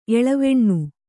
♪ eḷaveṇṇu